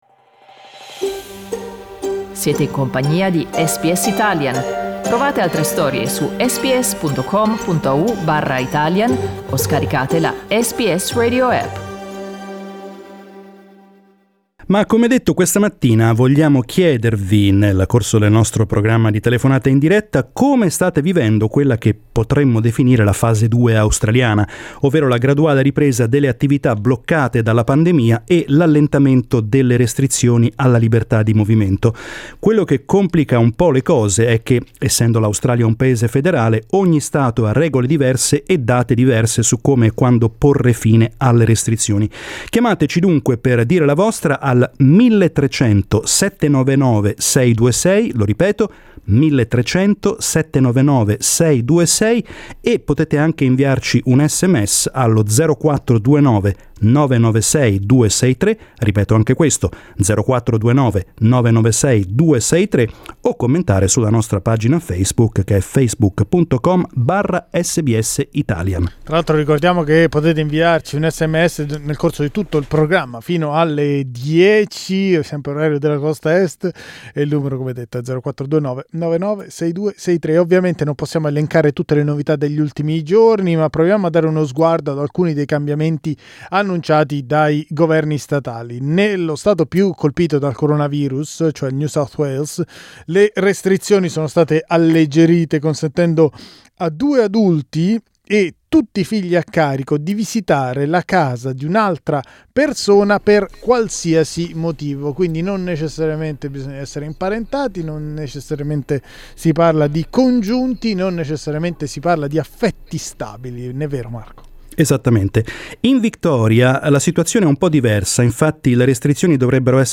We talked about it today with our listeners.